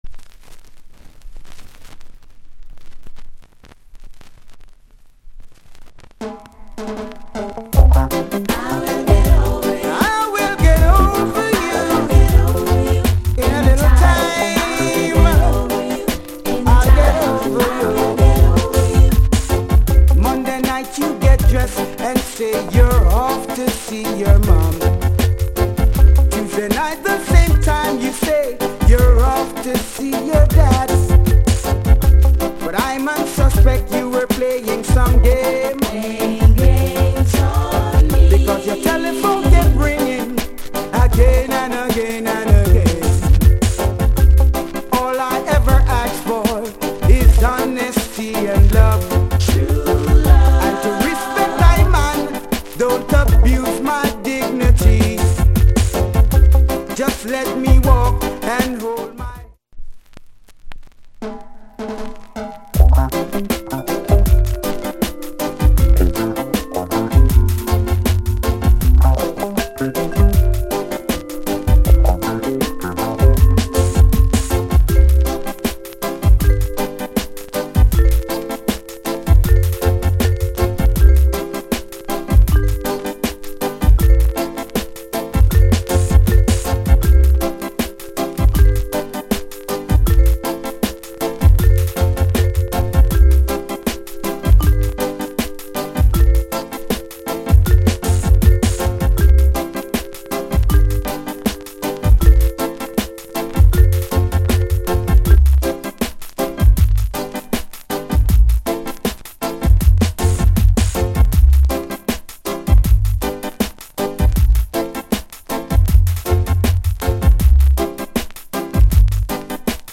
* Good Vocal.